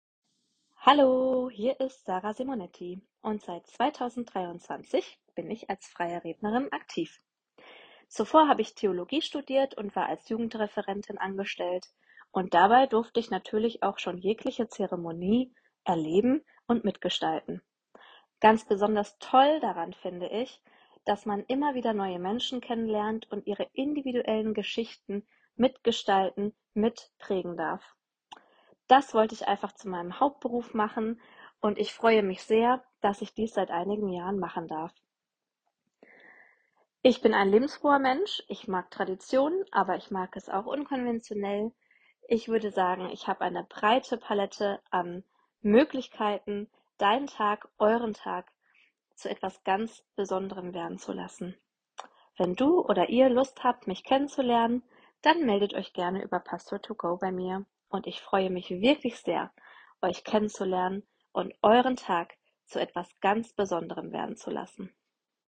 So klingt meine Stimme